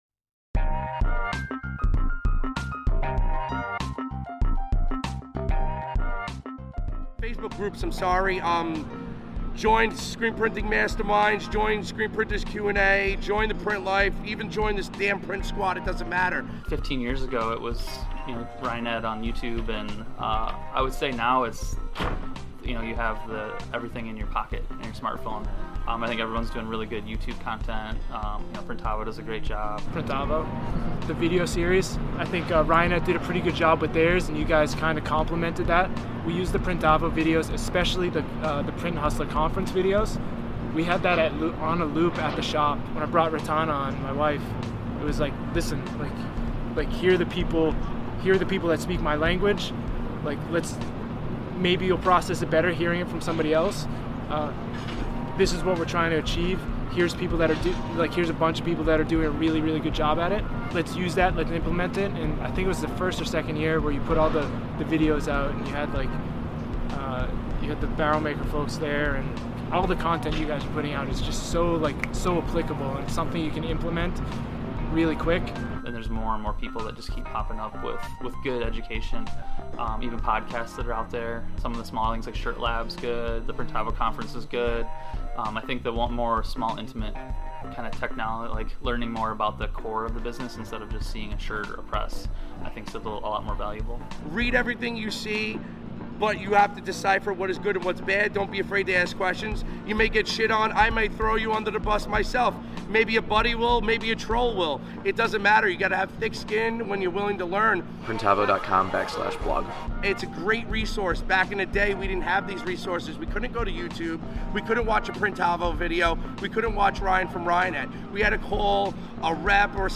In January 2020 we interviewed some of the industry's best and brightest. Here's what they had to say about learning to screen print.